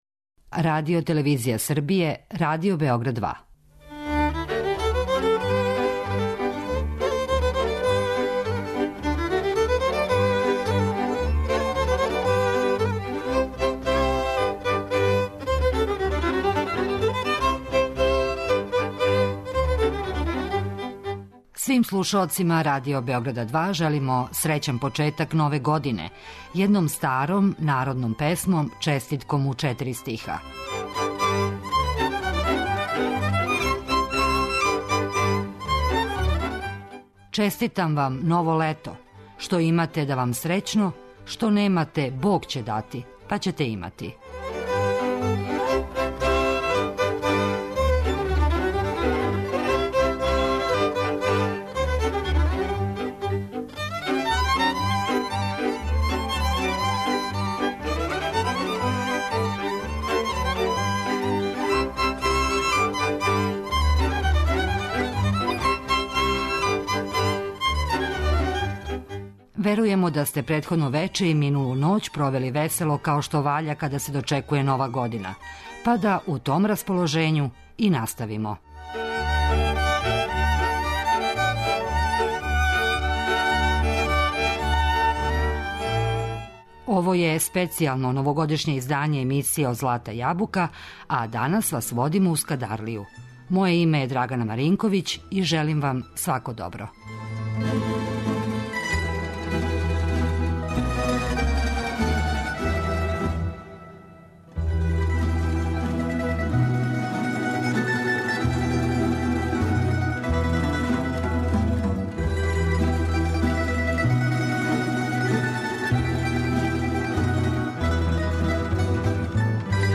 Данашњу емисију обојићемо песмом и причом о животу боемске Скадарлије.